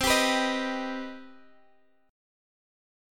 CmM7 chord